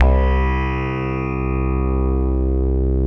53 BASS 1 -L.wav